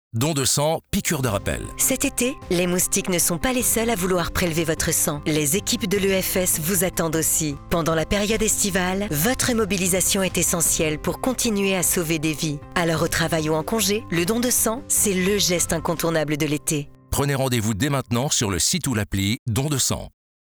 Spot Radio - moustique.wav